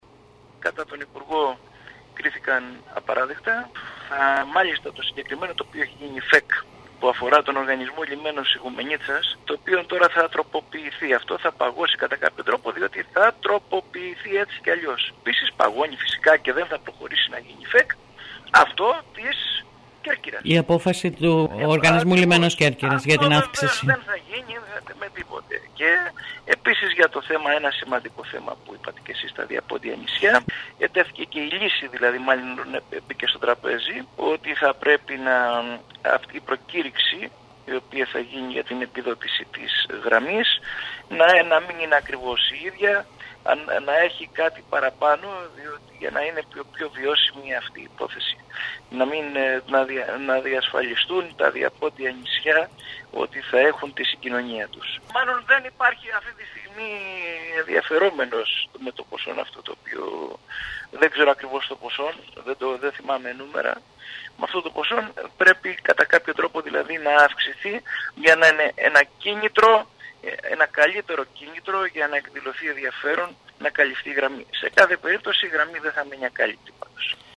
Ακούμε τον αντιπεριφερειάρχη νησιωτικής πολιτικής Σπύρο Γαλιατσάτο.